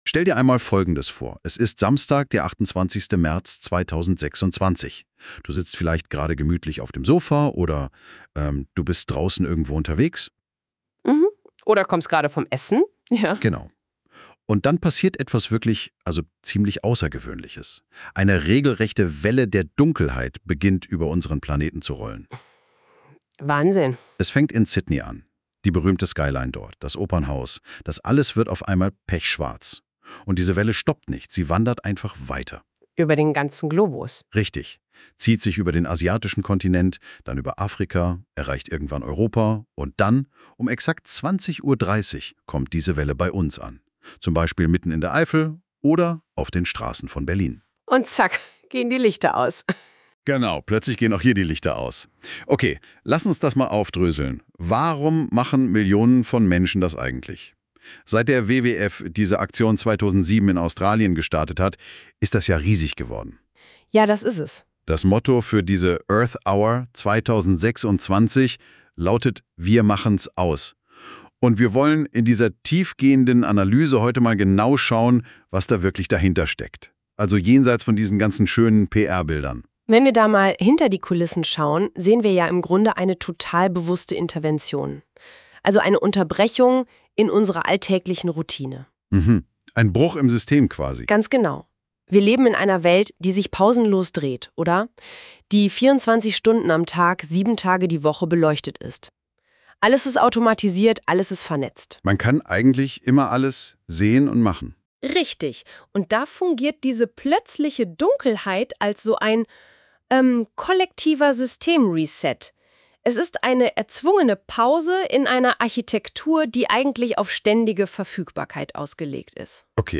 Audio-Dialog zur Earth Hour 2026 (KI-generiert)